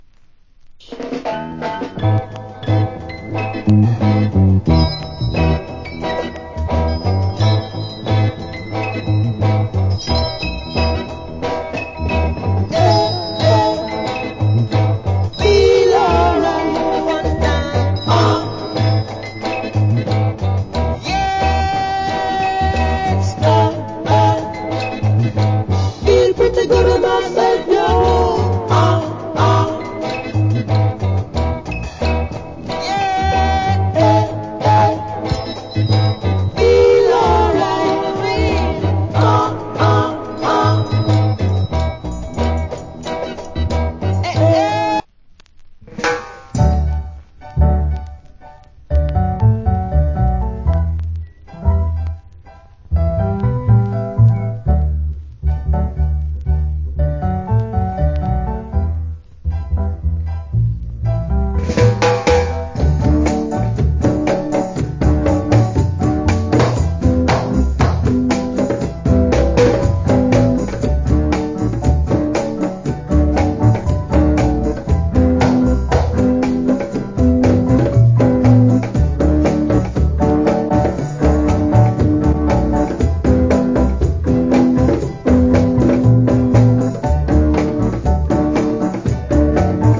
Cool Early Reggae Vocal.